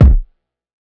Kick [Dash].wav